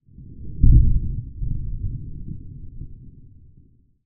THUNDER_Rumble_02_mono.wav